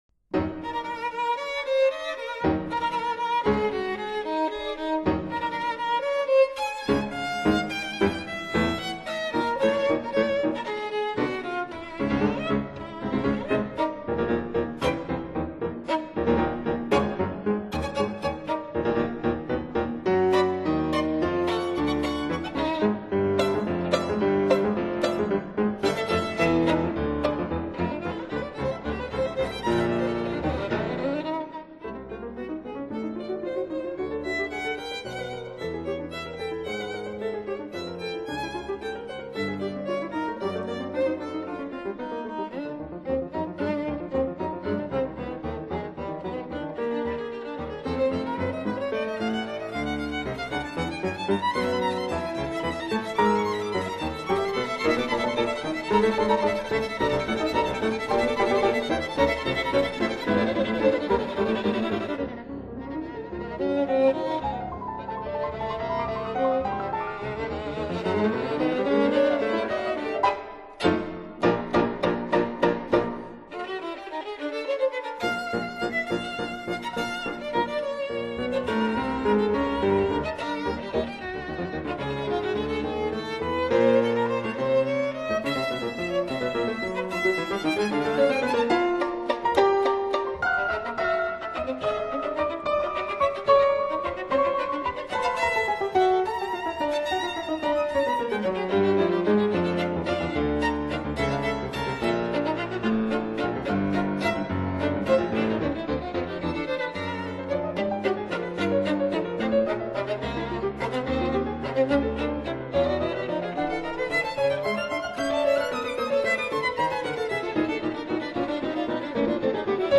分辑信息：CD20-22 室内乐